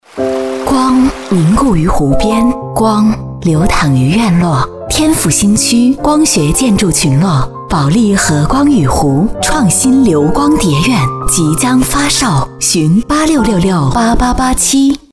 女国65_广告_地产_保利_亲切.mp3